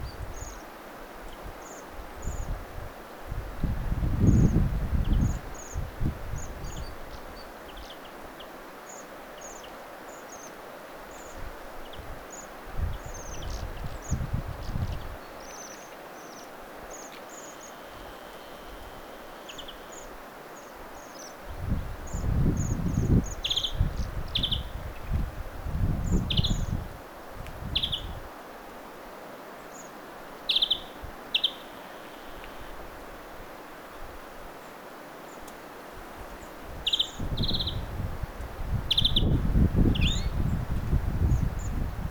tilhien ääniä, viherpeipon ääniä
tilhien_aania_viherpeipon_aantelya.mp3